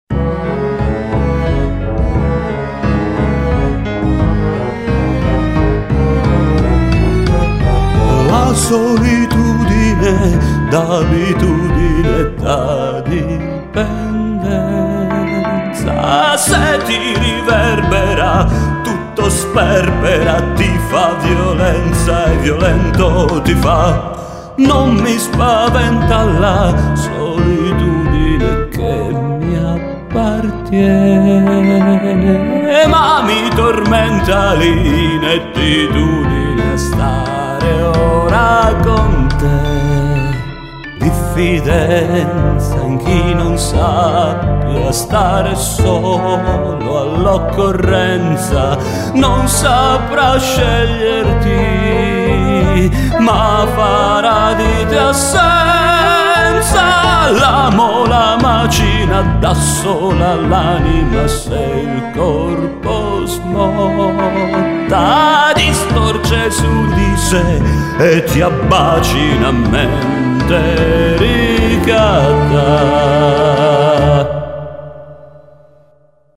spettacolo musicale tragicomico (come tutto è)